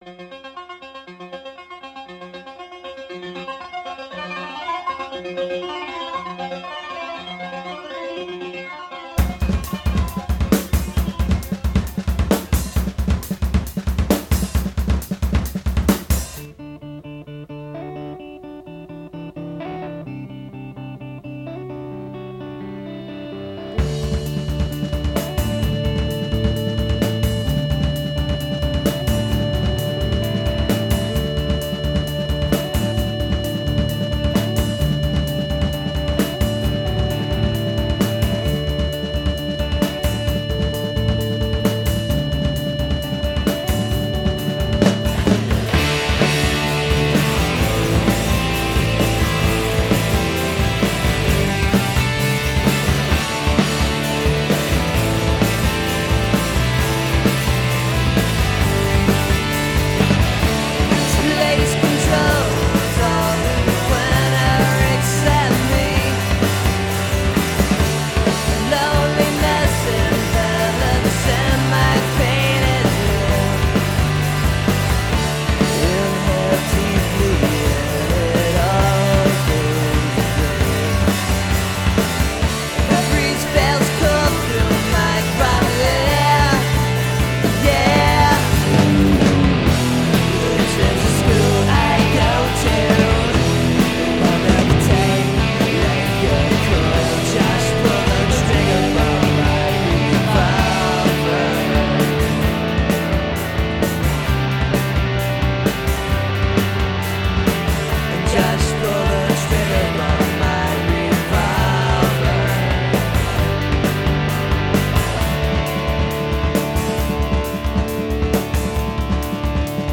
Recorded August 1994 at Dessau, NYC